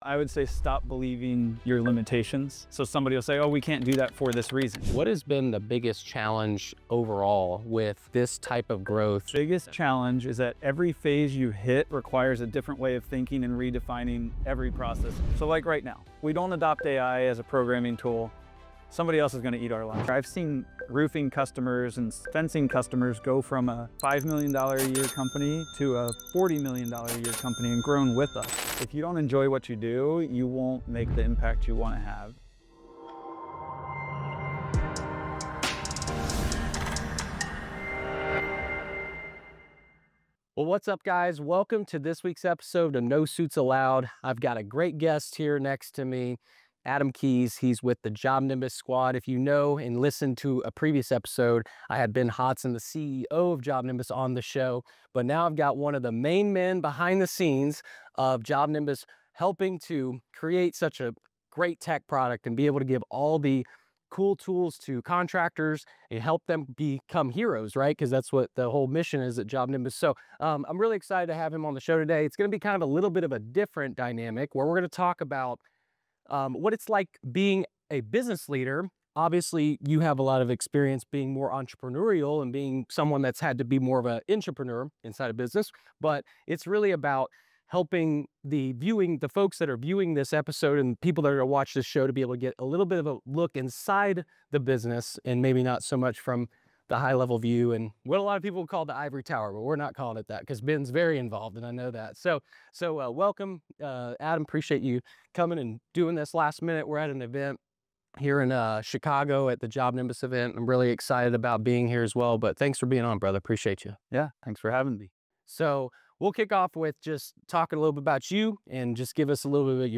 recorded live at a Job Nimbus event in Chicago.